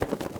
Babushka / audio / sfx / Animals / SFX_Duck_Wings_07.wav
SFX_Duck_Wings_07.wav